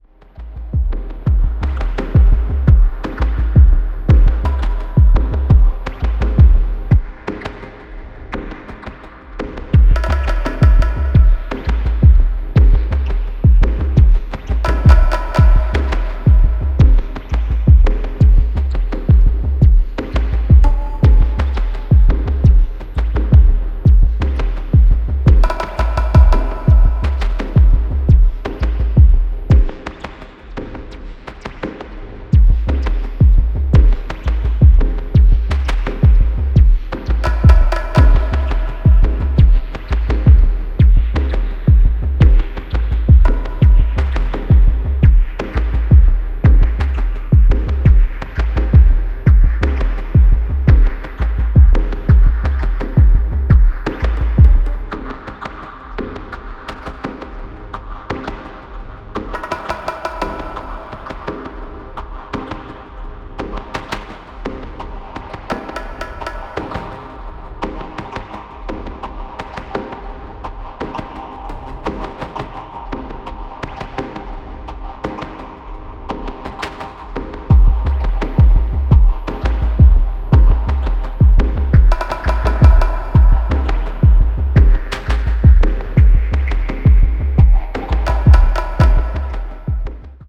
非常に先進的、かつ神聖さすら感じさせるDNB表現を堂々開陳